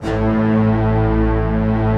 Index of /90_sSampleCDs/Optical Media International - Sonic Images Library/SI1_Fast Strings/SI1_Fast Tutti